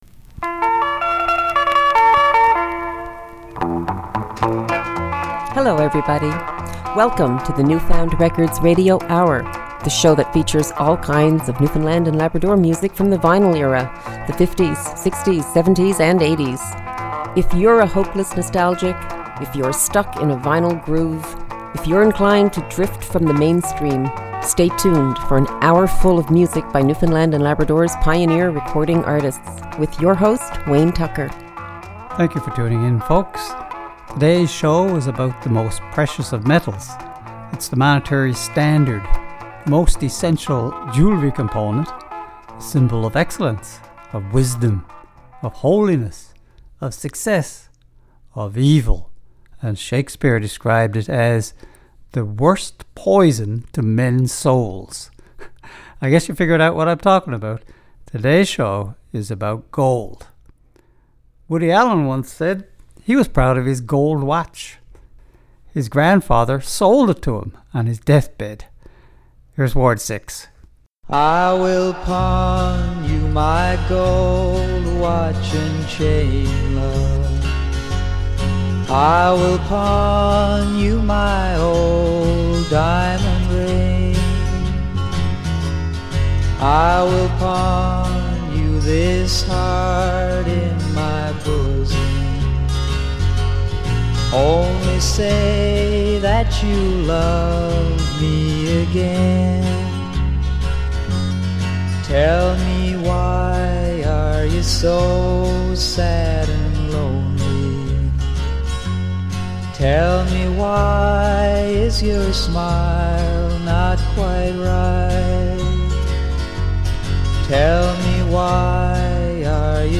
Recorded at CHMR studios, MUN, St. John's, NL.